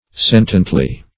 sentiently - definition of sentiently - synonyms, pronunciation, spelling from Free Dictionary
sentiently - definition of sentiently - synonyms, pronunciation, spelling from Free Dictionary Search Result for " sentiently" : The Collaborative International Dictionary of English v.0.48: Sentiently \Sen"ti*ent*ly\, adv.